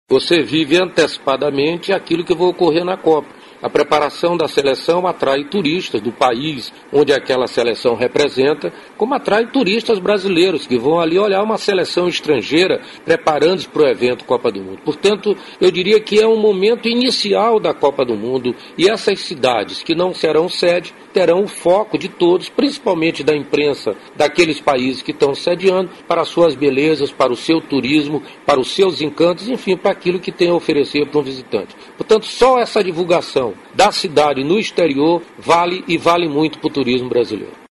aqui para ouvir declaração do ministro Gastão Vieira sobre a importância da presença de seleções estrangeiras em cidades de apoio da Copa.